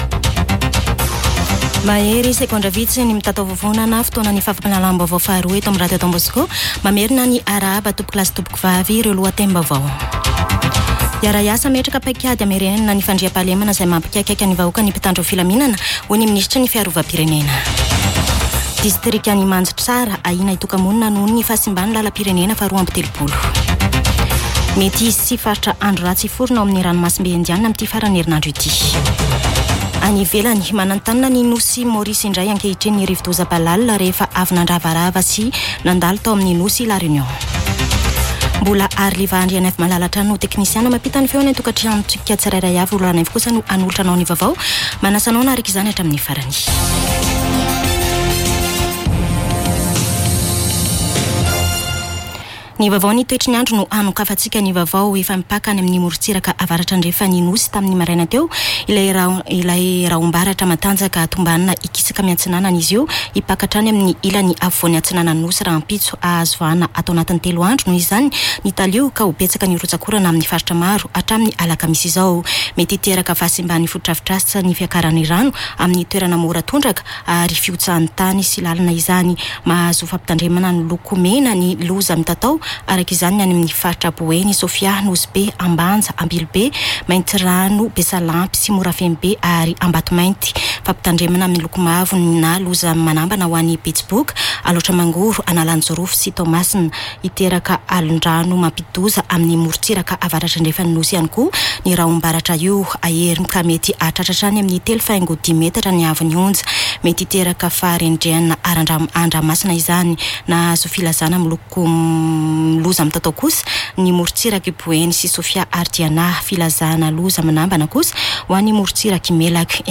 [Vaovao antoandro] Talata 16 janoary 2024